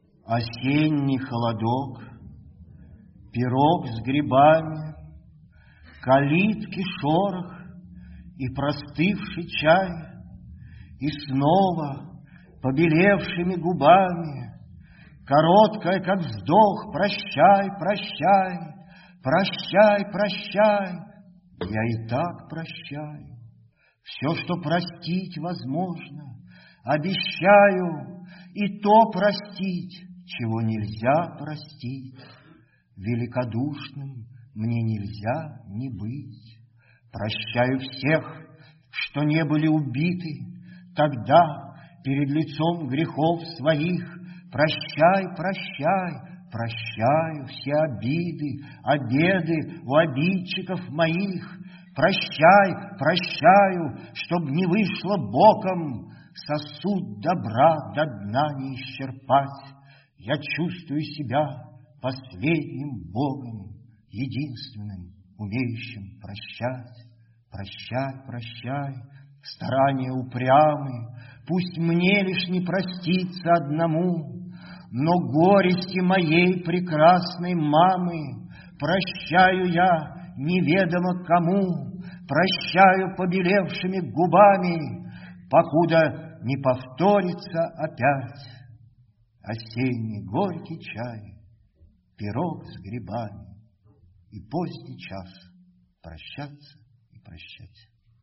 Булат Окуджава читает стихотворение